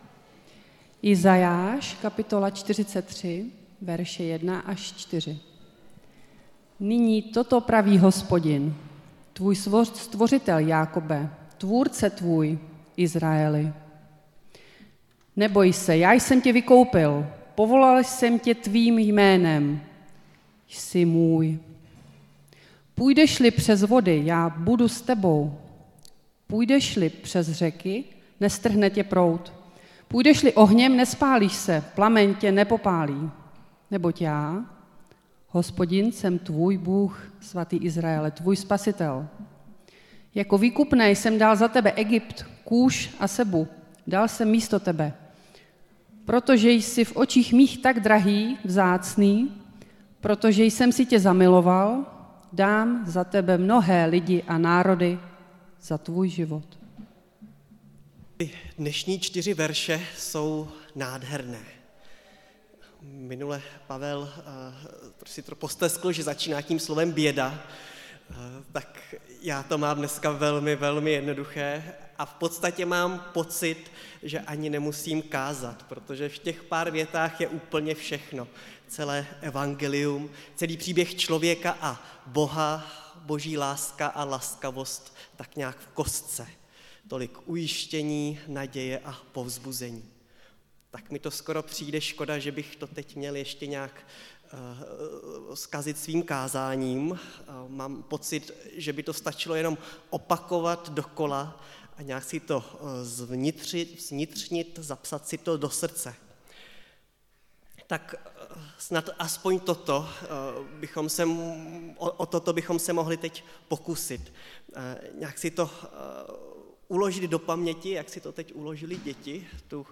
Nedělní kázání – 27.3.2023 Tváří v tvář novým začátkům